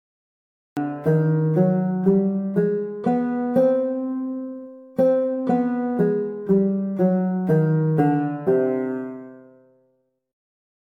Arabic-scale_nawa_atar.mp3